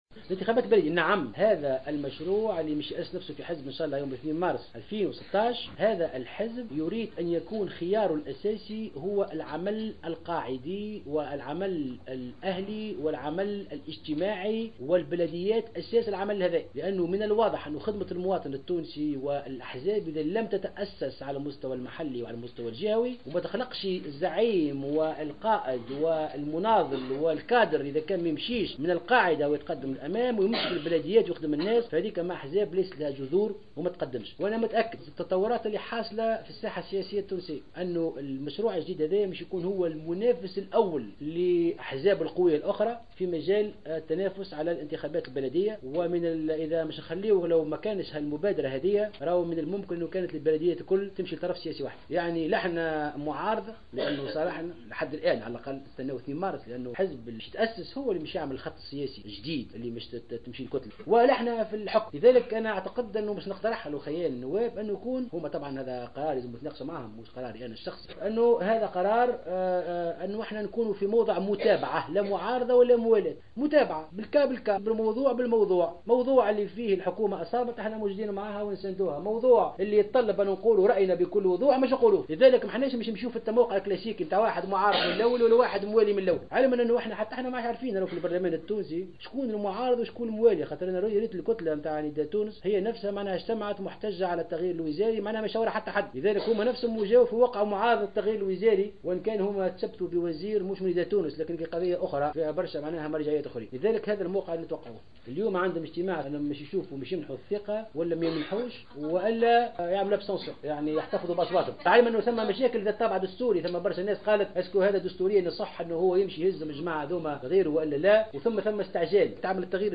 قال أمين عام حزب نداء تونس المستقيل محسن مرزوق خلال اجتماع عقده اليوم الأحد 10 جانفي 2016 بقصر المؤتمرات بالعاصمة إنه سيشارك في الانتخابات البلدية بعد تأسيس حزبه الذي سيعلن عنه في 02 مارس الحالي.